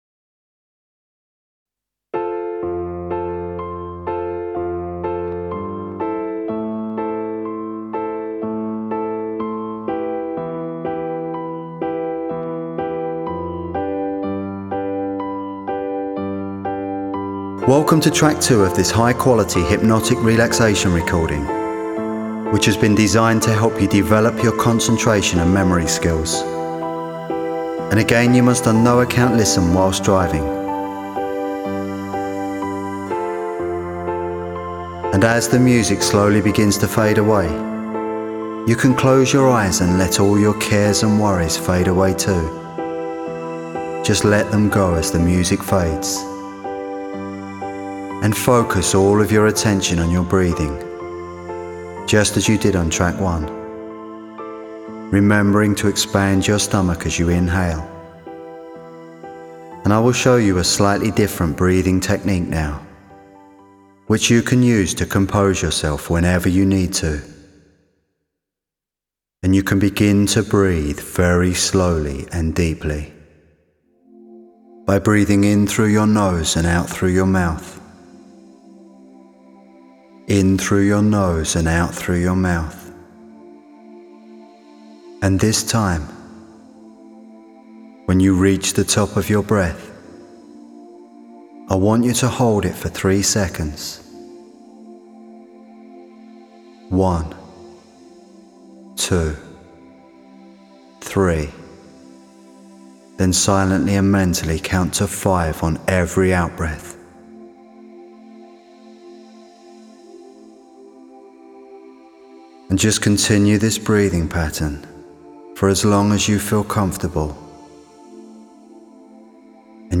This audio program can be used for any type of exam preparation or to improve concentration and develop a stronger memory. In both hypnotherapy sessions, you will hear a pleasant English voice and absorbing sound effects guiding you into a deeply relaxed state of mental and physical relaxation.